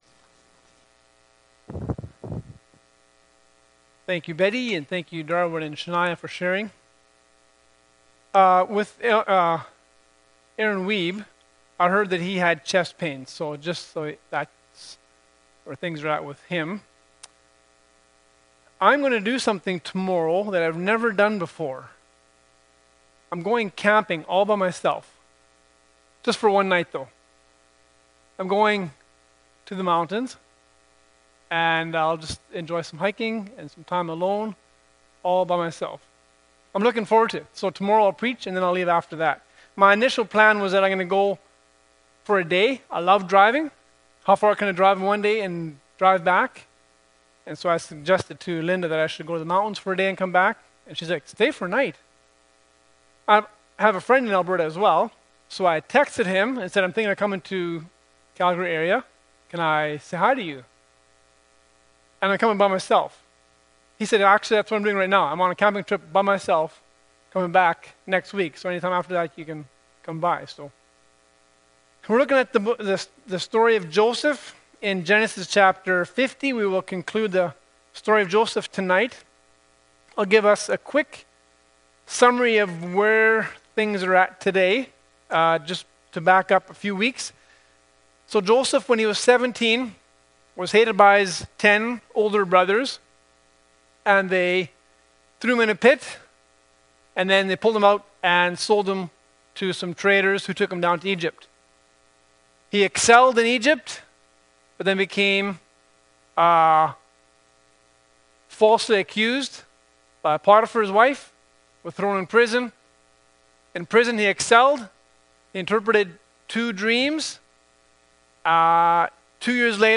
Genesis 50:15-21 Service Type: Sunday Morning Bible Text